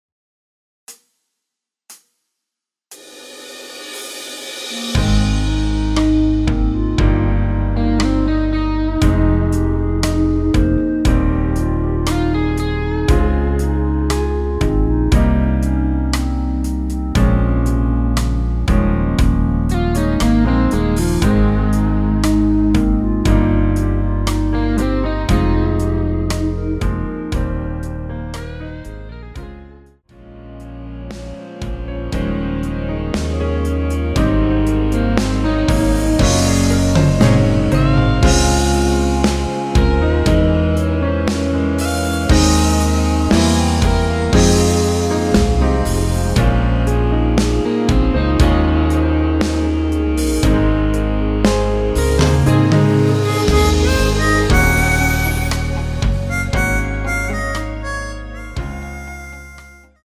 전주없이 시작하는 곡이라 카운트 4박 넣어 놓았습니다.(미리듣기 참조)
원키에서(-1)내린 멜로디 포함된 MR입니다.
Ab
앞부분30초, 뒷부분30초씩 편집해서 올려 드리고 있습니다.
중간에 음이 끈어지고 다시 나오는 이유는